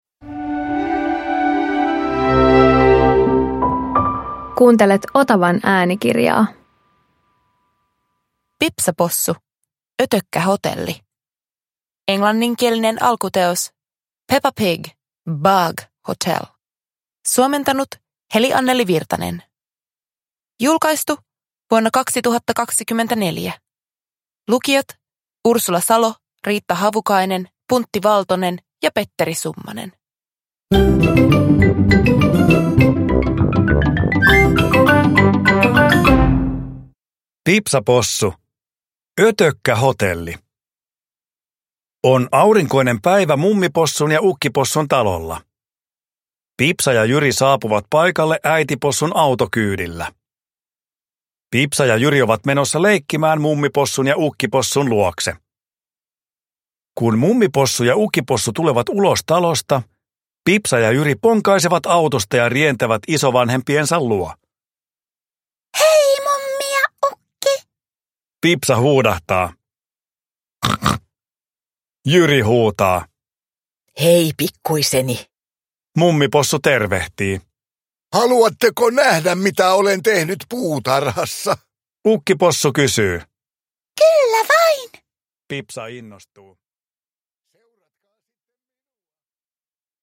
Pipsa Possu - Ötökkähotelli – Ljudbok